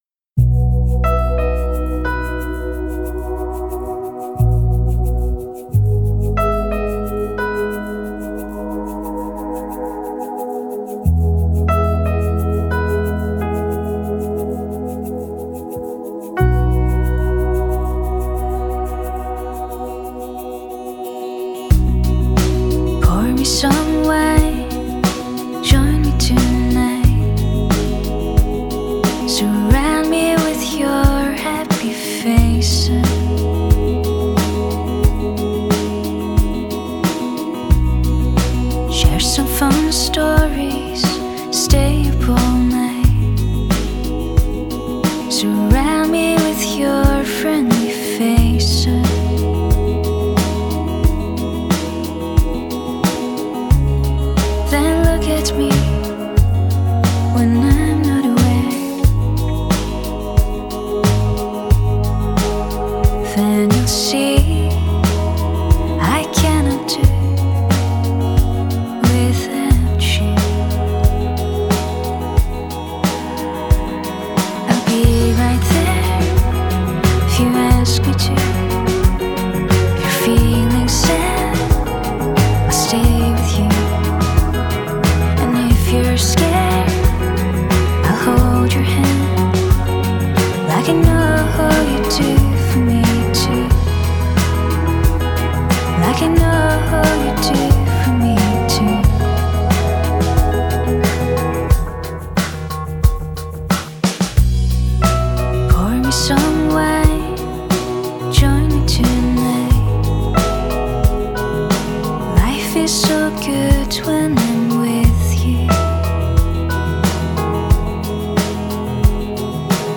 МУЗЫКА ИЗ РЕКЛАМ (JINGLE)